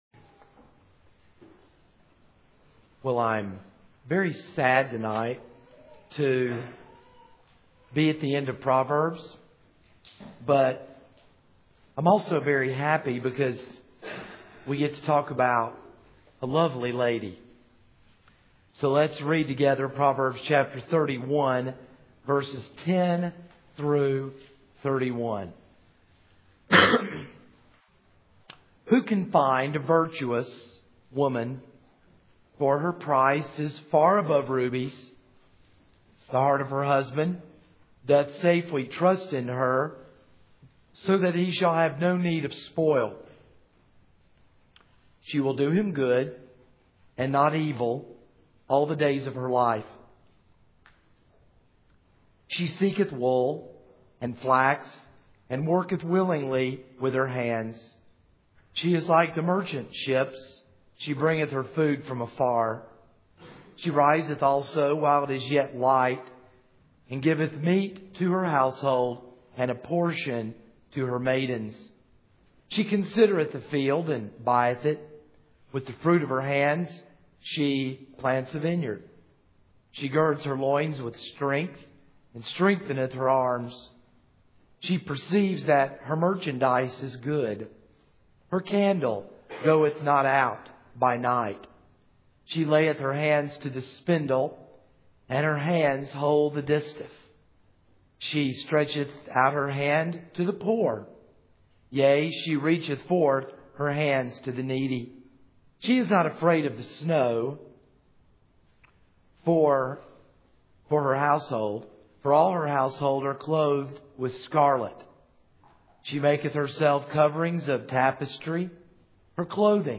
This is a sermon on Proverbs 31:10-31.